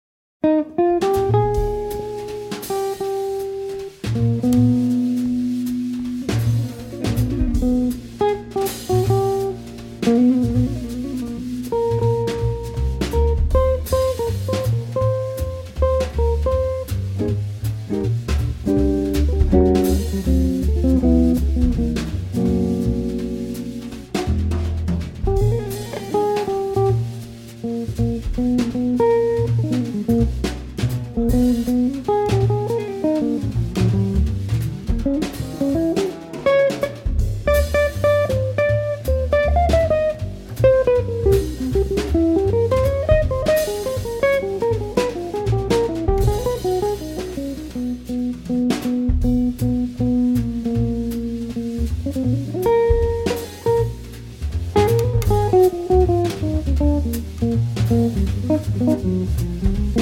guitar.
drums
bass.